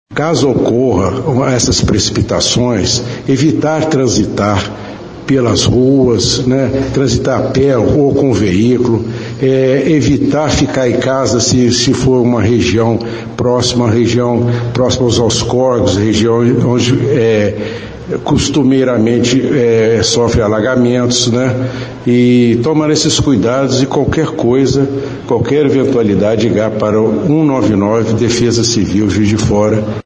O subsecretário também destacou medidas preventivas para a população.